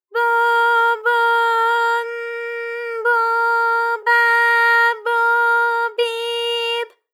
ALYS-DB-001-JPN - First Japanese UTAU vocal library of ALYS.
bo_bo_n_bo_ba_bo_bi_b.wav